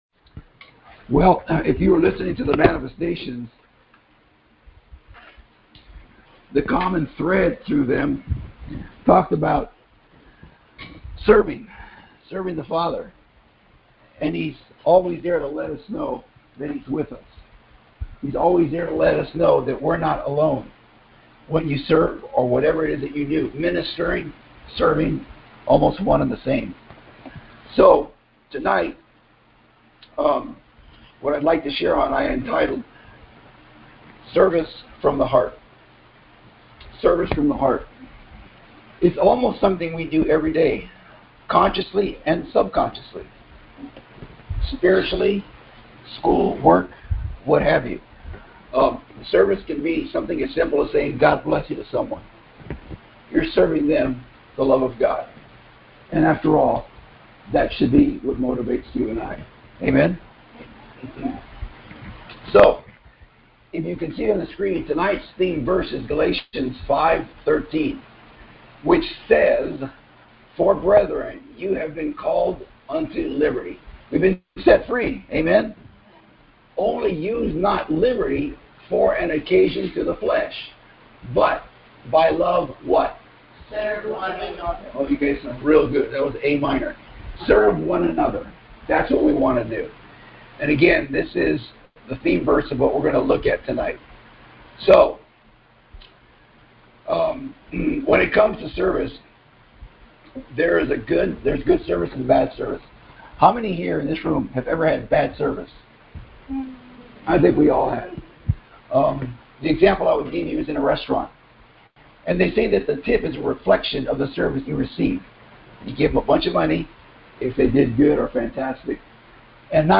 Scripture: Proverbs 29:18 Play the sermon